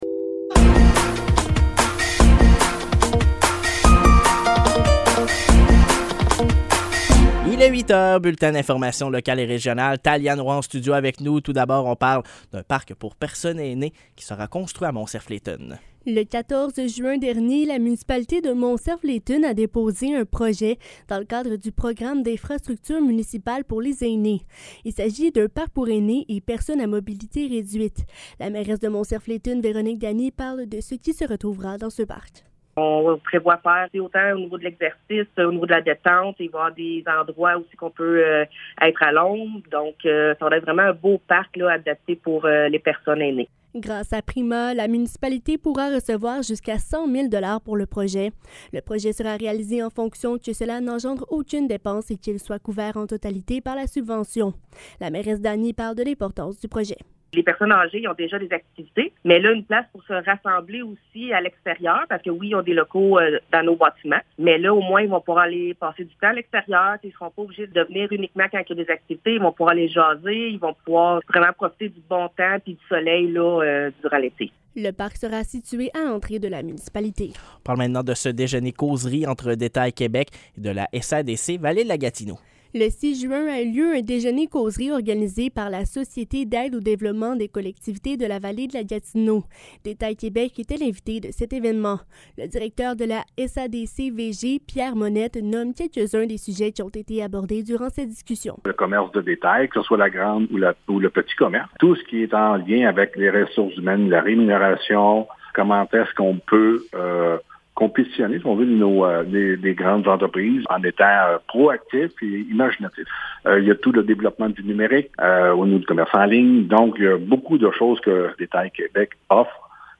Nouvelles locales - 19 juin 2023 - 8 h